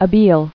[a·bele]